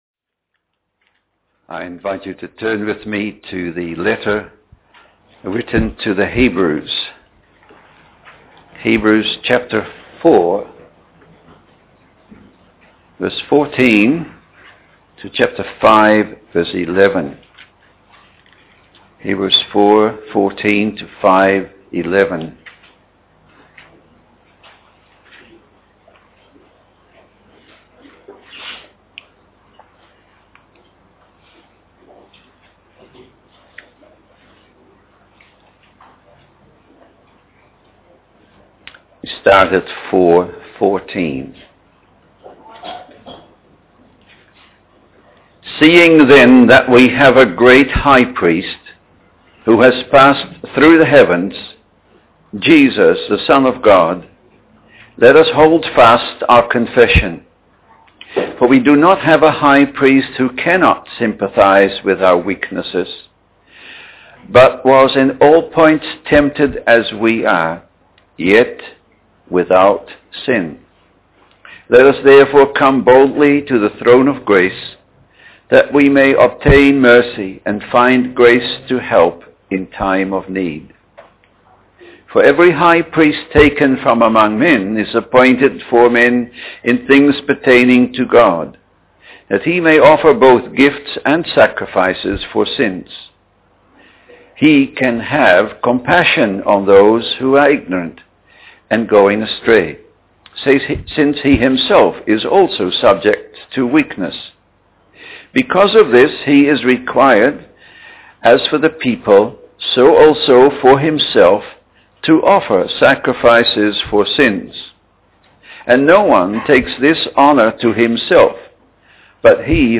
Preached on the 3rd of February 2008.